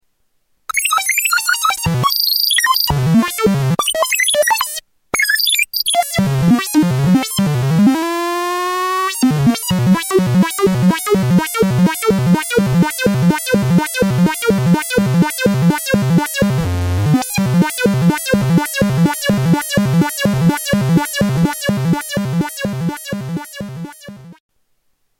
Category: Sound FX   Right: Personal
Tags: Sound Effects EML ElectroComp 101 EML101 ElectroComp 101 Synth Sounds